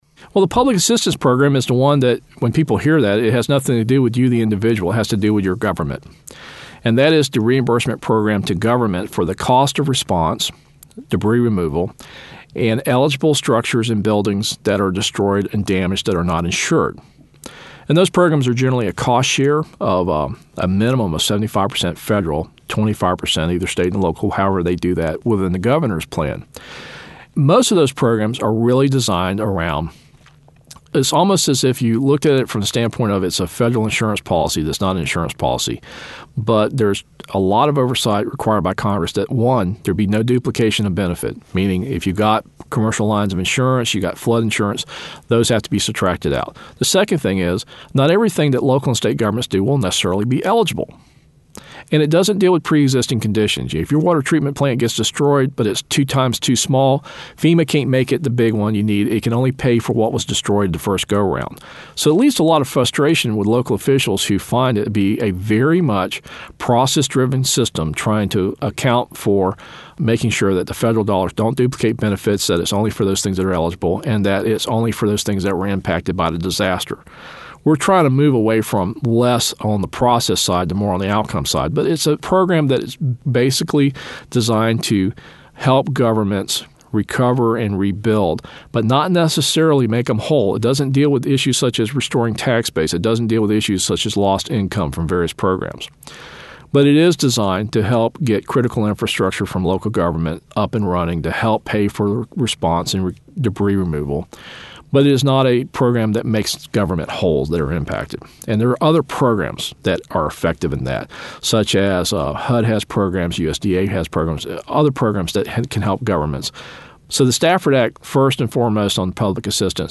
A Conversation with W. Craig Fugate, FEMA Administrator